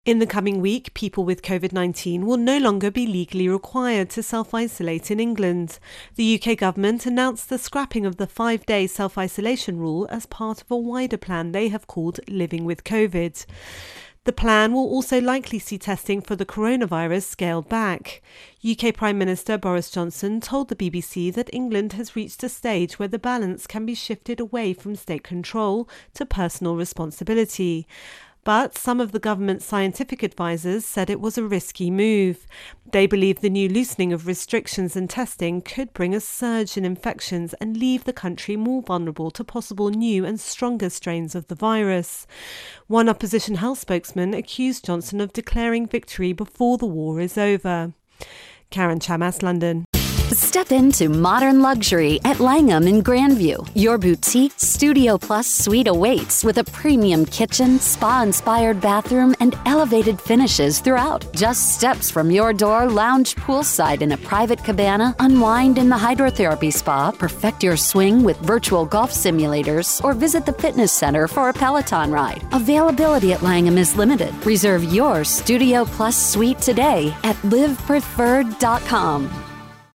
Intro and voicer on Virus Outbreak Britain.